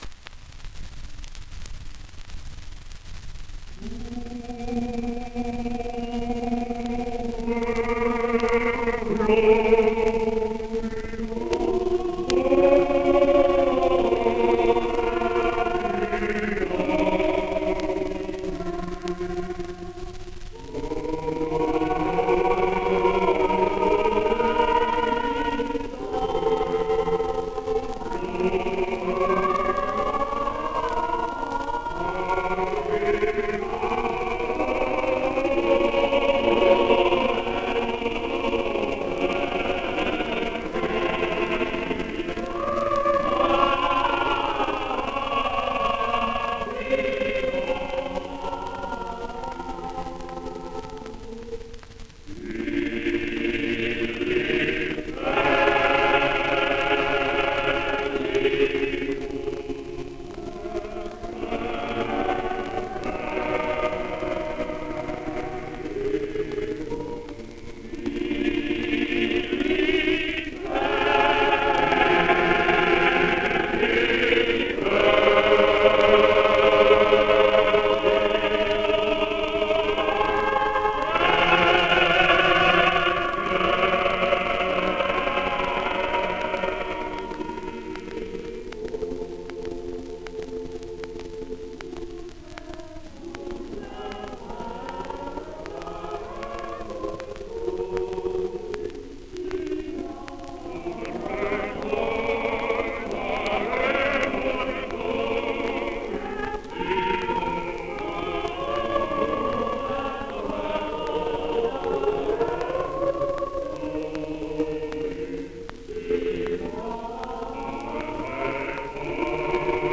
78 rpm, mono ; 30 cm Intérprete: Coro de la Capilla Giulia (a cappella)